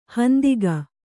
♪ handiga